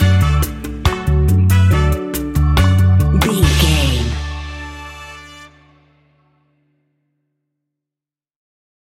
Classic reggae music with that skank bounce reggae feeling.
Ionian/Major
D
Slow
dub
laid back
chilled
off beat
drums
skank guitar
hammond organ
percussion
horns